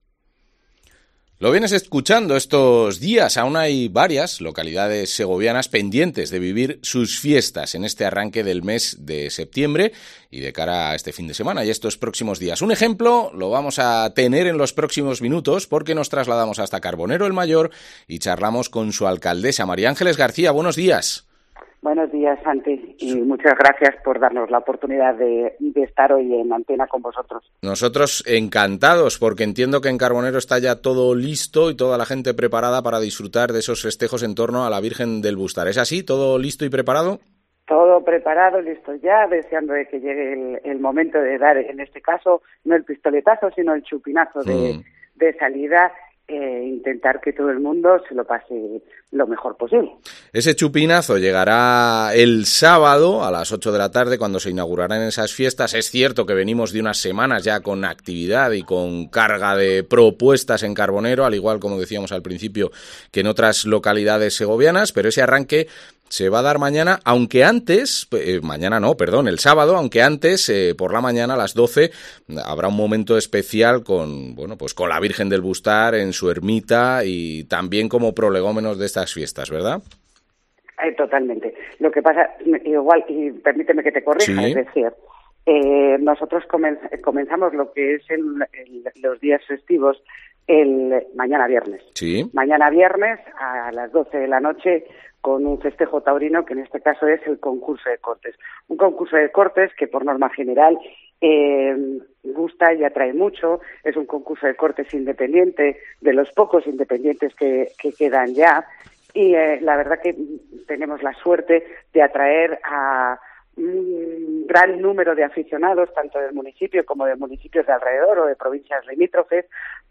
Mª Ángeles García, alcaldesa de Carbonero el Mayor